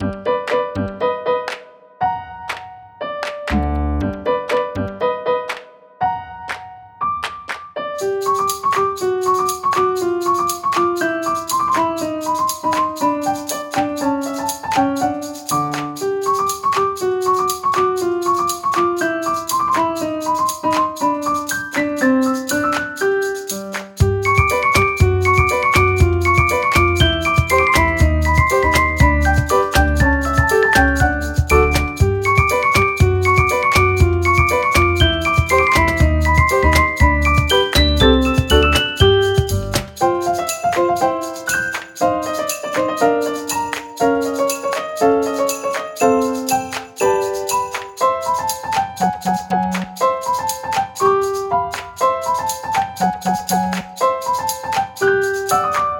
明るい楽曲
【イメージ】おでかけ、やんちゃな子ねこ など